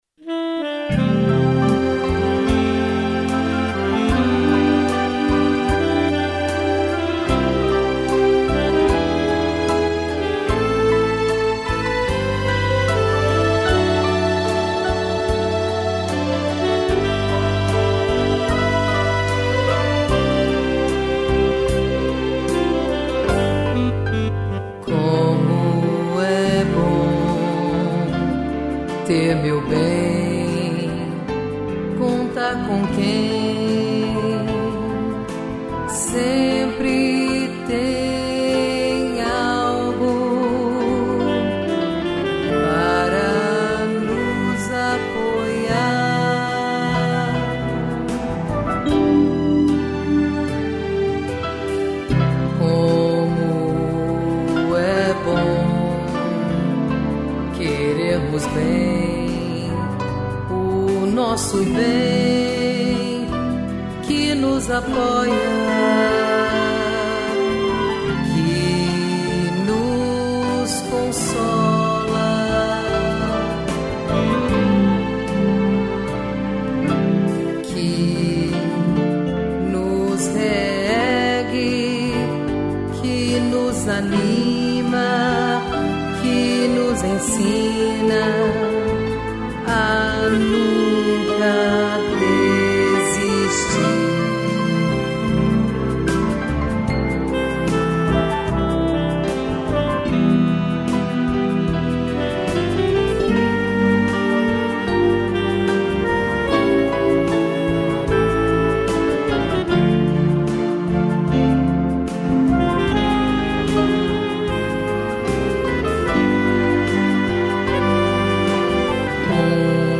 piano, sax e strings